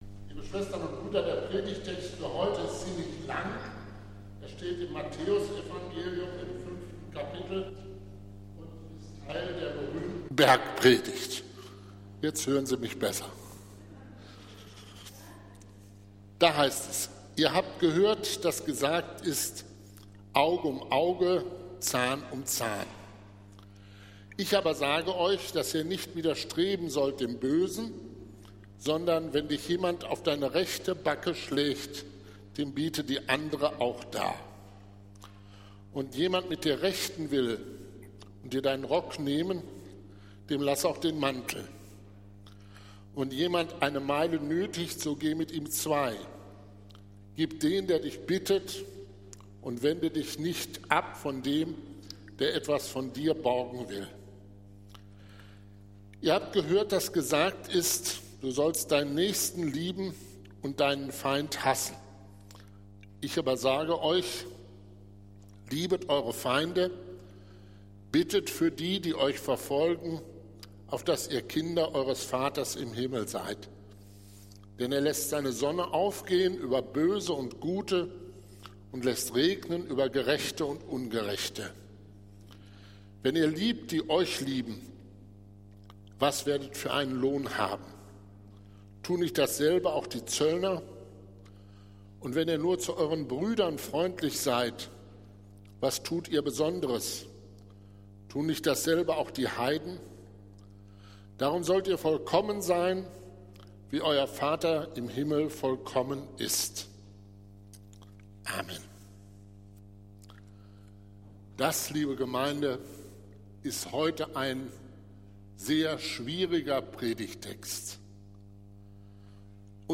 Predigt des Gottesdienstes aus der Zionskirche vom Sonntag, 20. Oktober 2024